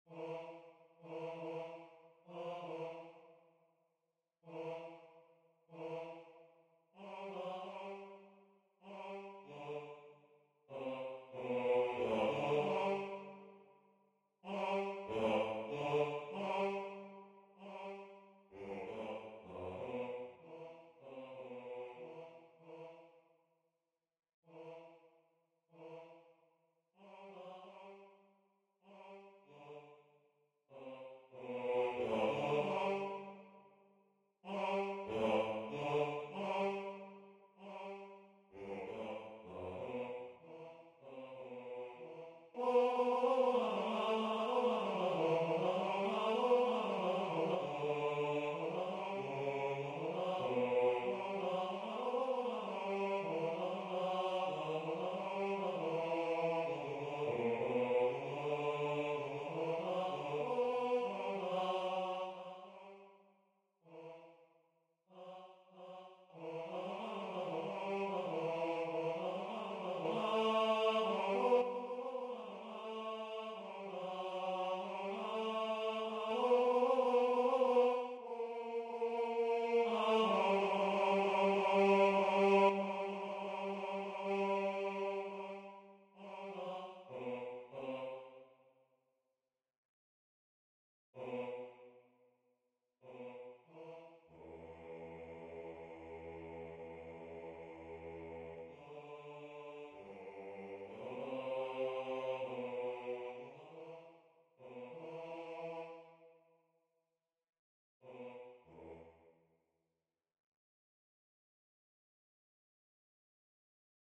ITZAYA-Basse.mp3